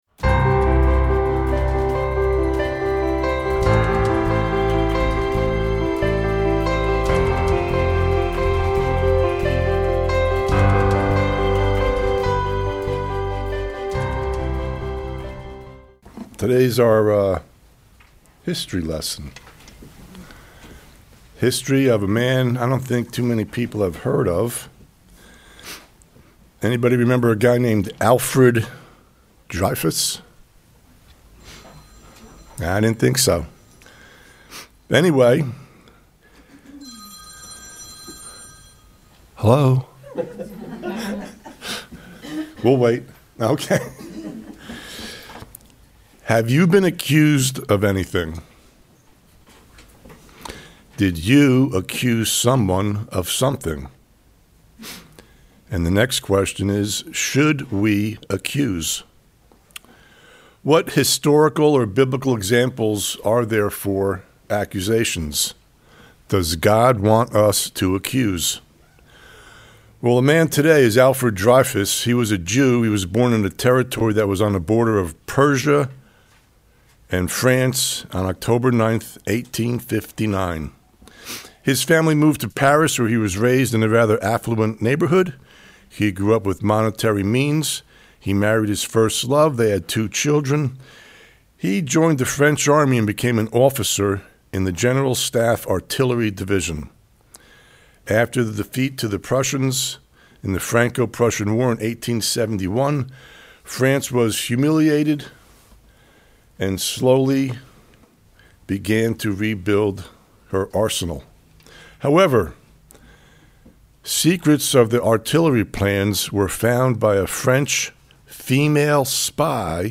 Sermons
Given in Charlotte, NC Hickory, NC Columbia, SC